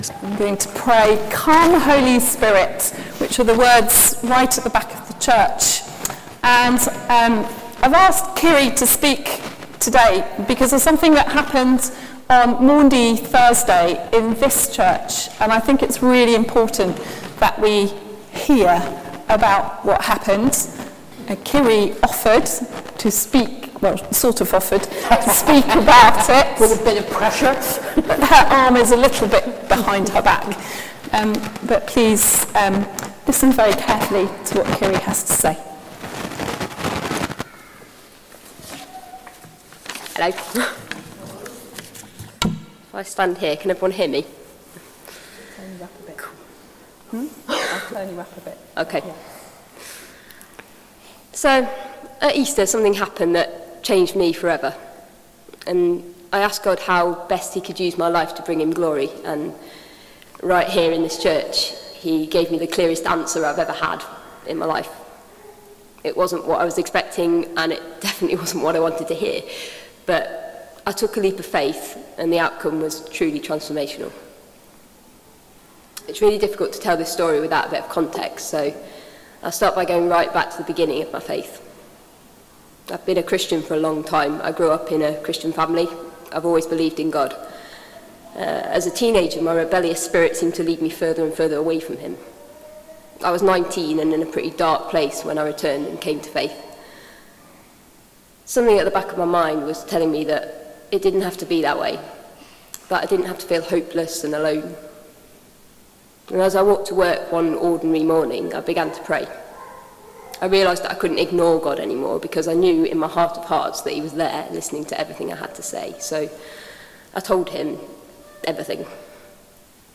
Sermon: Come Holy Spirit – Pentecost | St Paul + St Stephen Gloucester